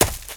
High Quality Footsteps
STEPS Leaves, Run 28.wav